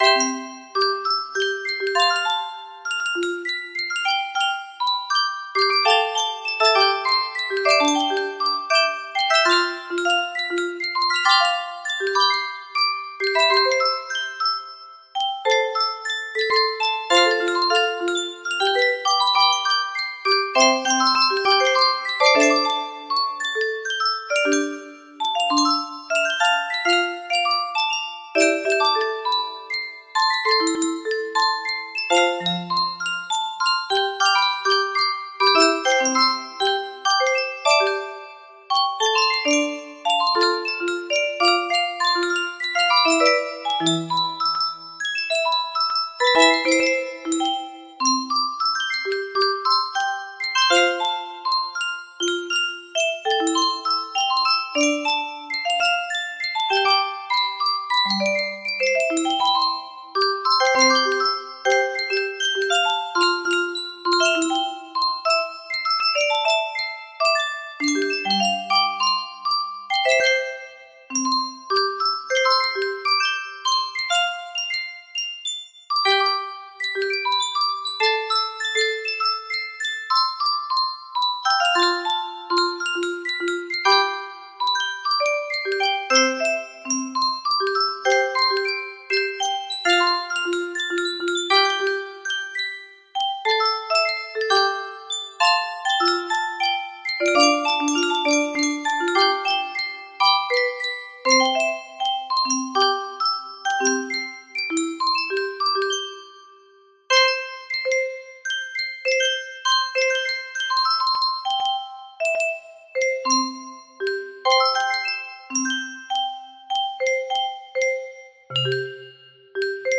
Threads Of Gold 3 music box melody
Full range 60